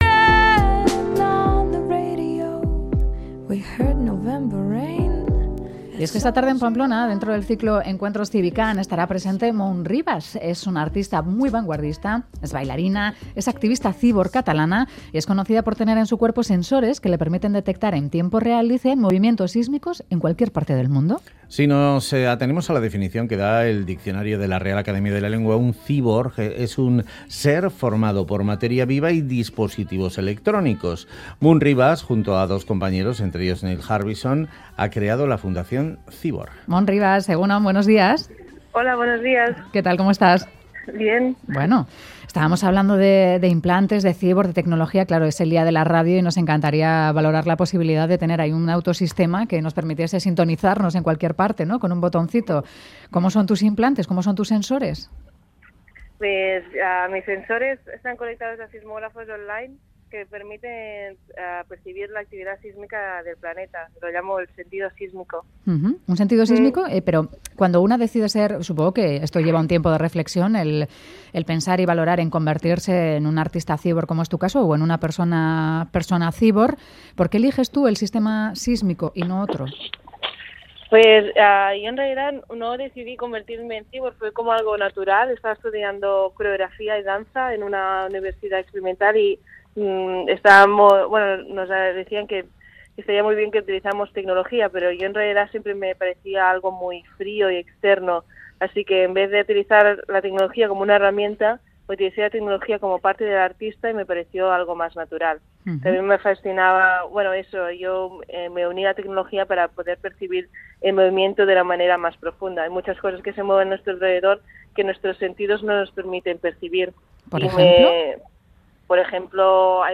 Audio: Moon Rivas, activisata cíborg, nos habla sobre su experiencia cíborg